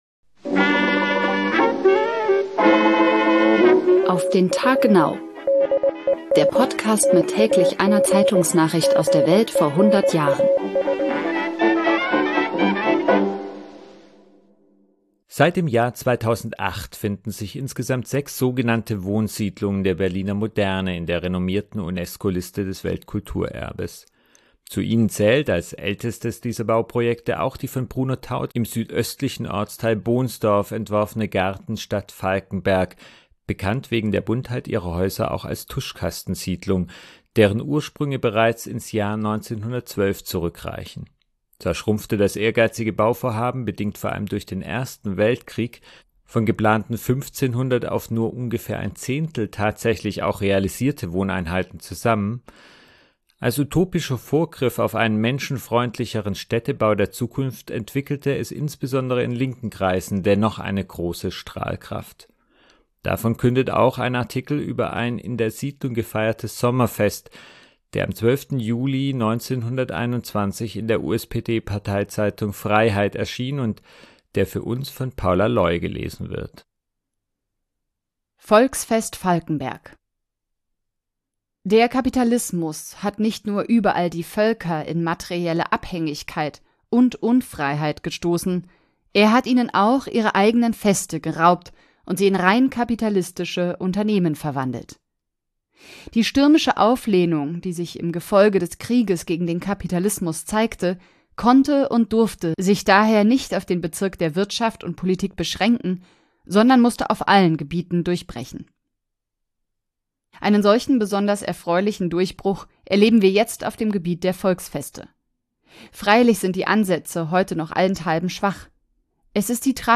gelesen wird.